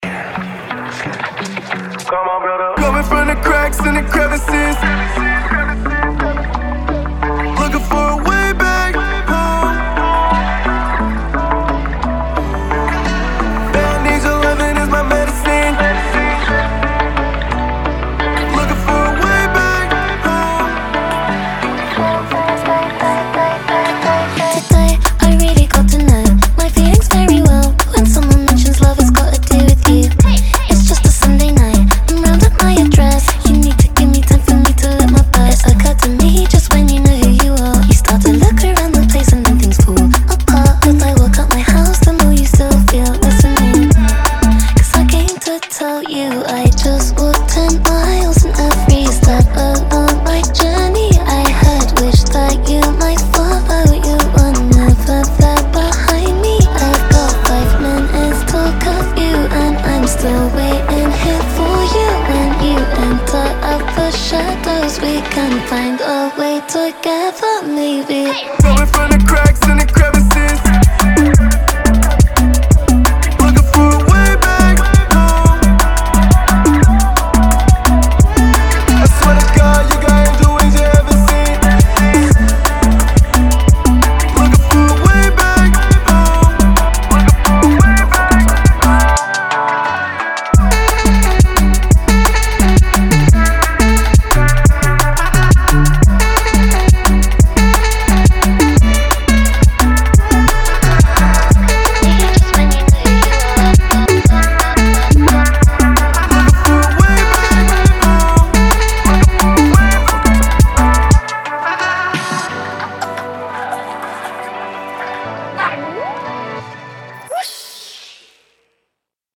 BPM175-175
Audio QualityPerfect (High Quality)
Drum & Bass song for StepMania, ITGmania, Project Outfox
Full Length Song (not arcade length cut)